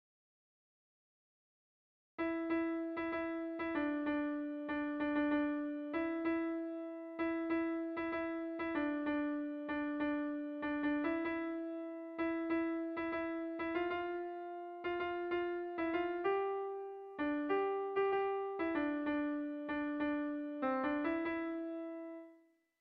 Bertso melodies - View details   To know more about this section
Tragikoa
Zortziko txikia (hg) / Lau puntuko txikia (ip)